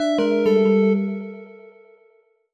jingle_chime_19_negative.wav